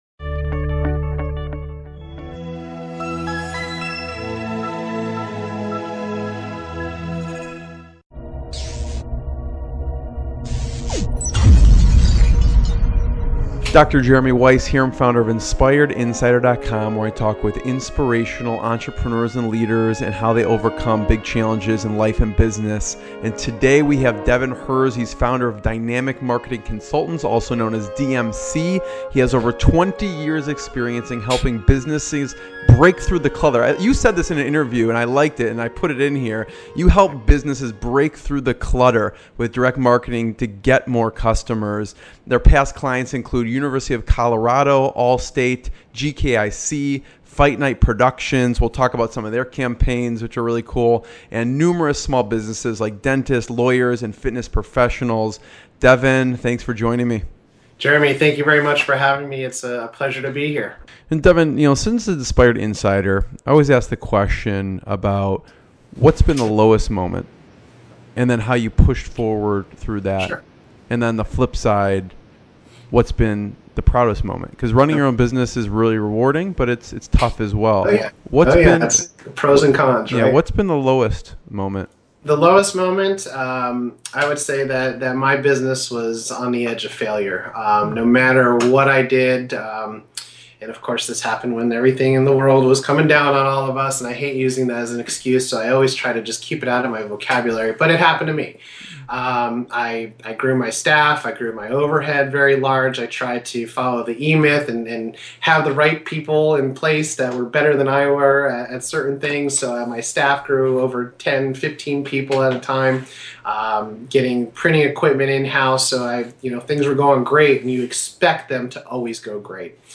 Inspirational Business Interviews with Successful Entrepreneurs and Founders